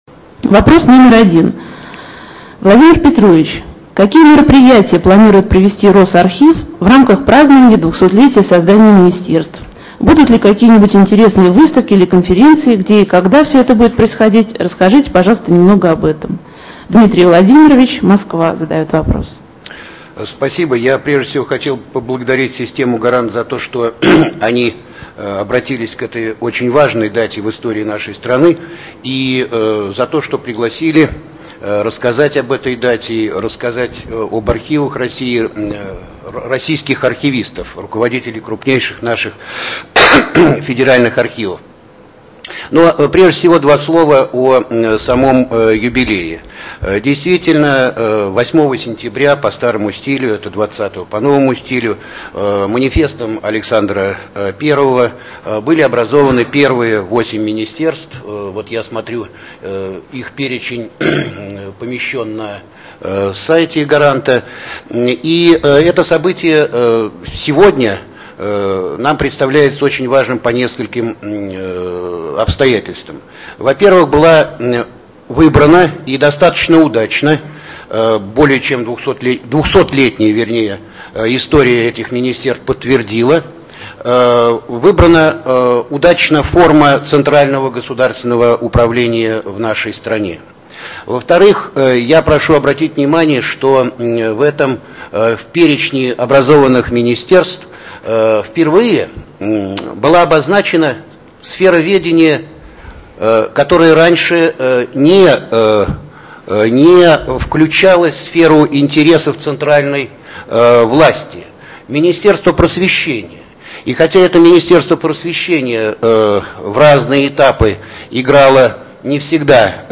Интернет-конференция